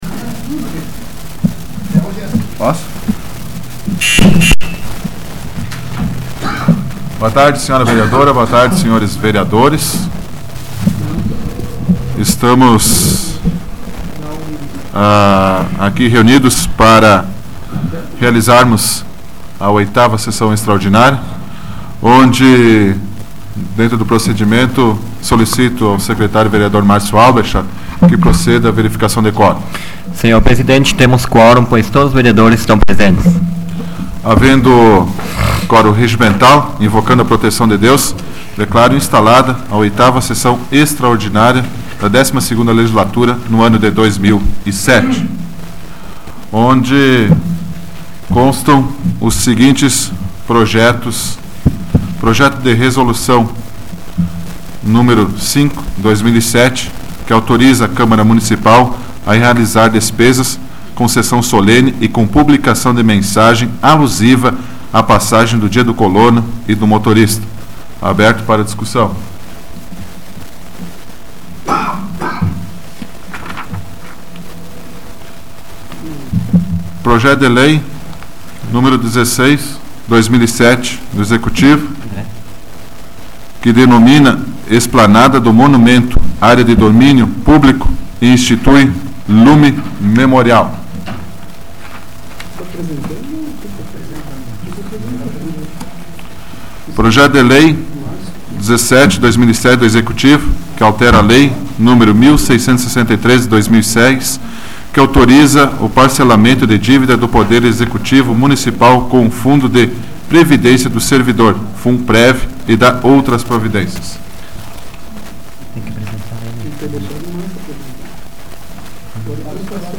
Áudio da 39ª Sessão Plenária Extraordinária da 12ª Legislatura, de 23 de julho de 2007